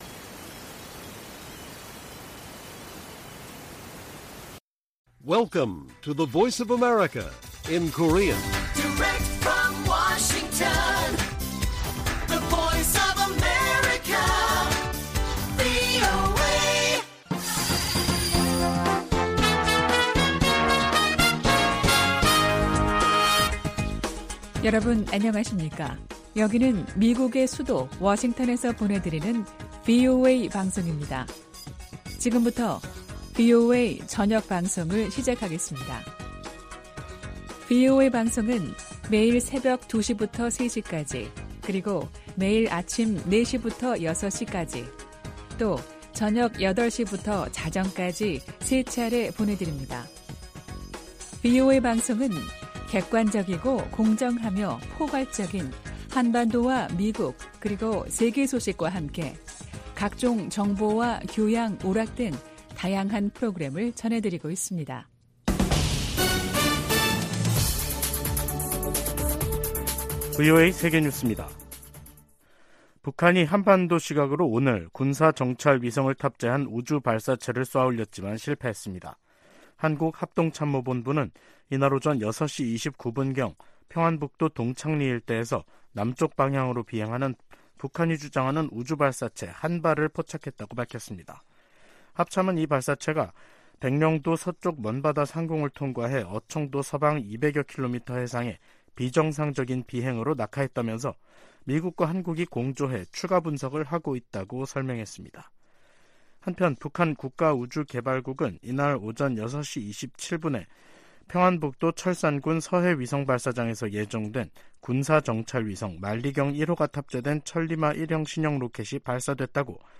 VOA 한국어 간판 뉴스 프로그램 '뉴스 투데이', 2023년 5월 31일 1부 방송입니다. 북한은 31일 정찰위성 발사가 엔진고장으로 실패했다고 발표했습니다. 폴 라캐머라 한미연합사령관 겸 주한미군사령관은 한국에 대한 미국의 방위 공약이 철통같다며 이를 의심하지 말아 달라고 당부했습니다. 세계 각국의 여성 인권 상황을 심의하는 유엔 기구가 중국에 탈북 여성들의 지위를 정상화하고 기본적인 권리를 보장할 것을 권고했습니다.